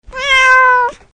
» мяу Размер: 21 кб